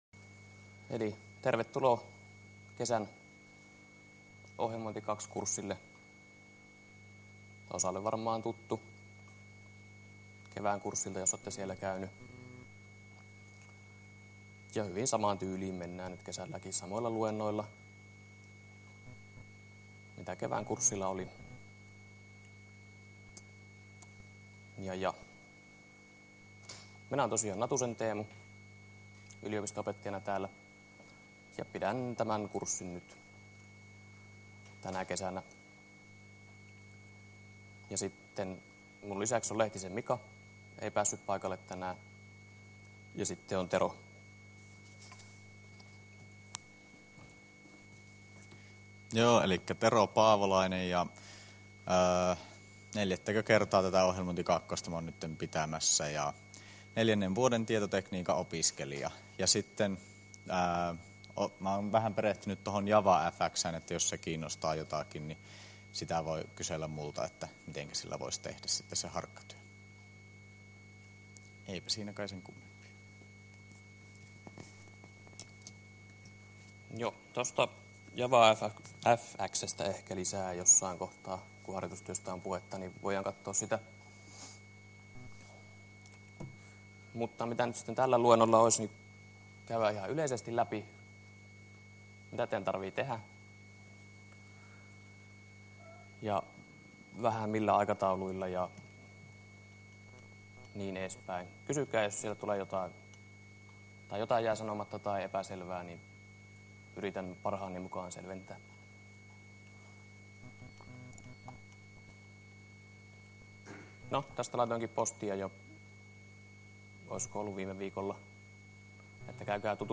Luento_kesa15